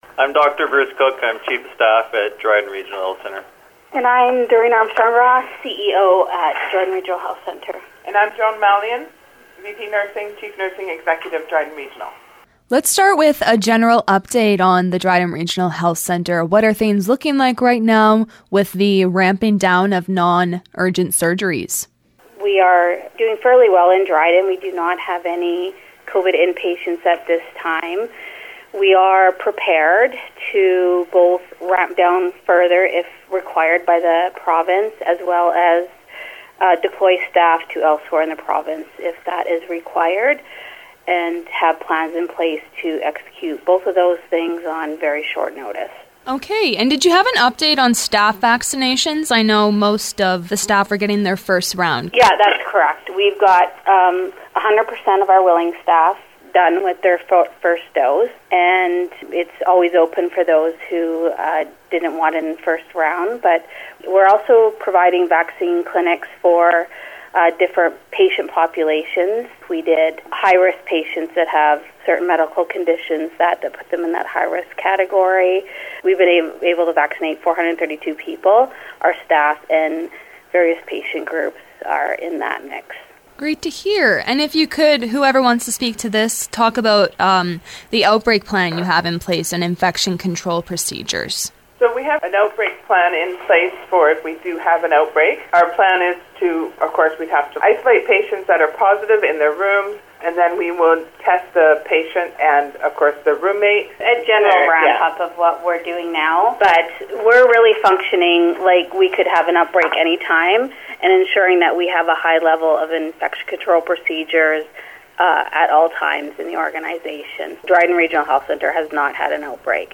drhc-interview.mp3